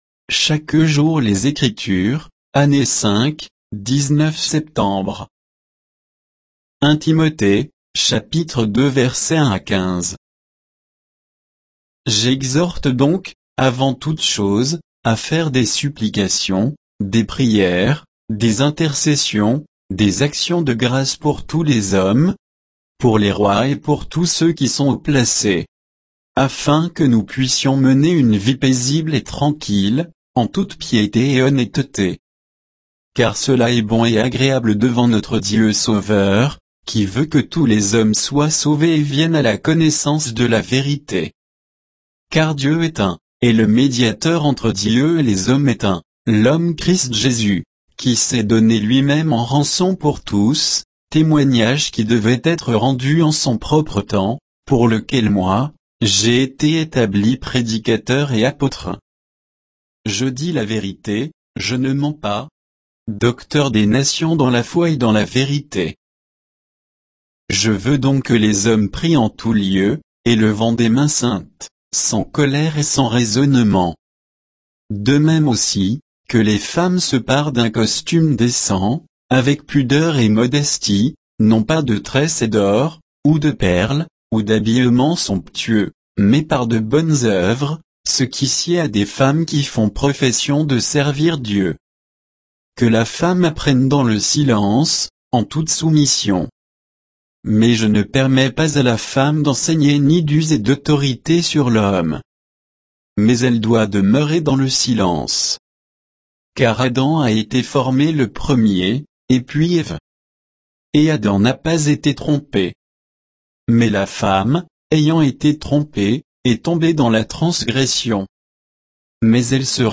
Méditation quoditienne de Chaque jour les Écritures sur 1 Timothée 2, 1 à 15